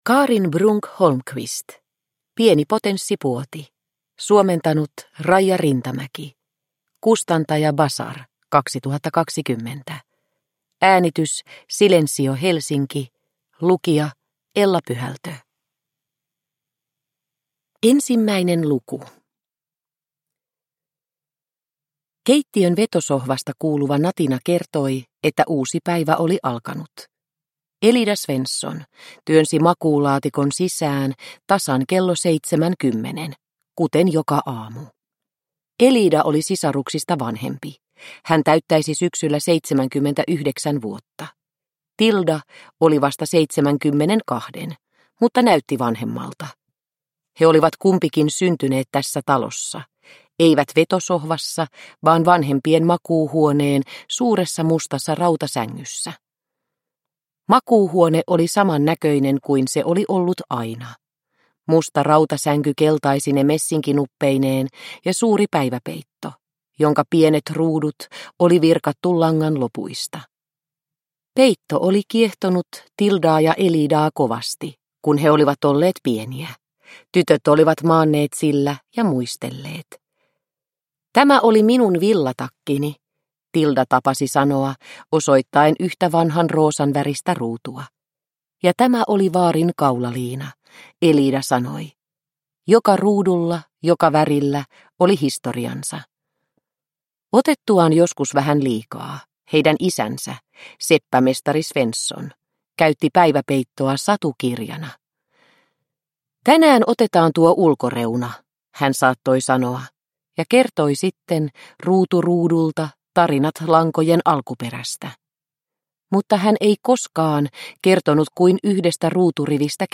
Pieni potenssipuoti – Ljudbok – Laddas ner